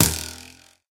bowhit3.ogg